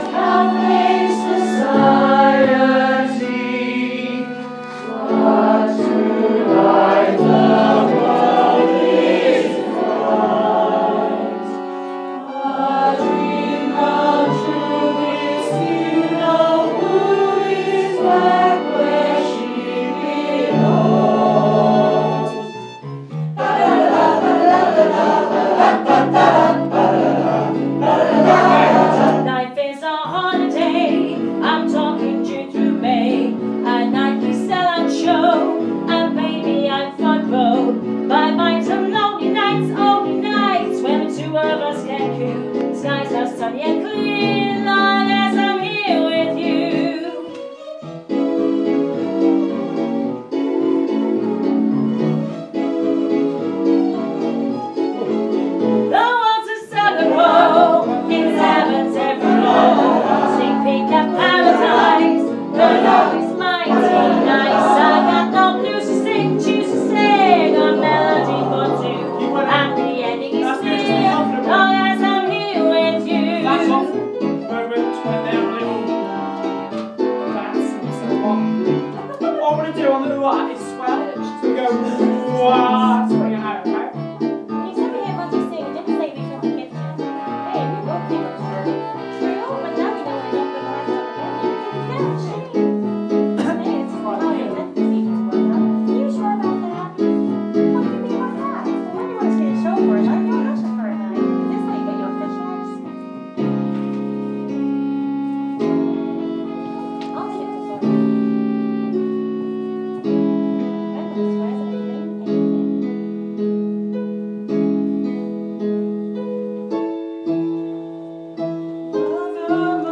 TMM Rehearsal